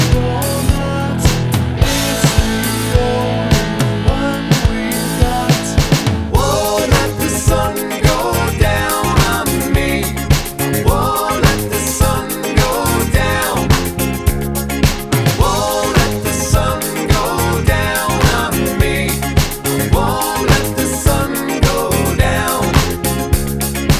One Semitone Down Pop (1980s) 3:18 Buy £1.50